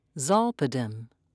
(zol'-pi-dem)